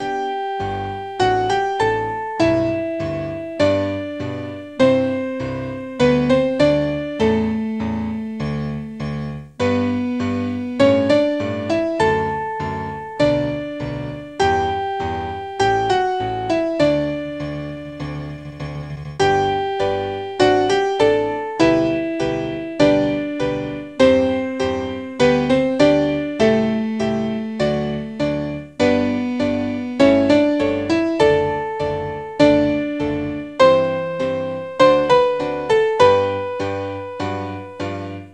クラシック